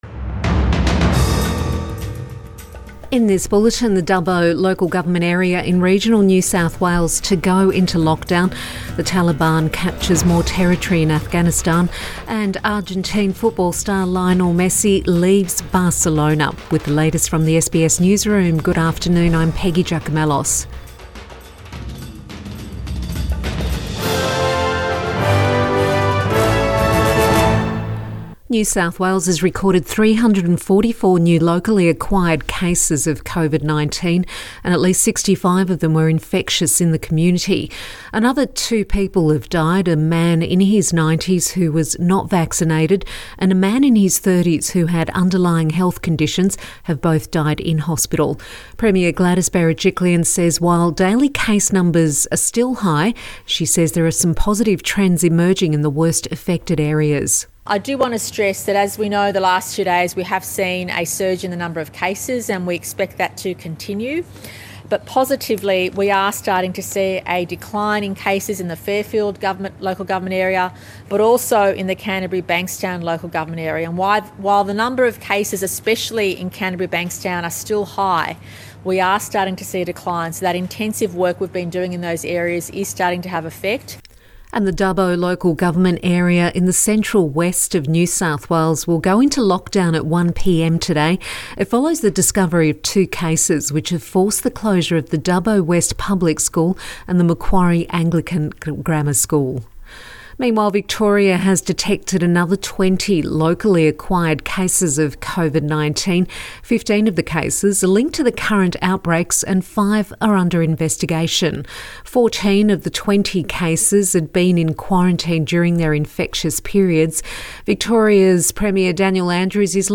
Midday bulletin 11 August 2021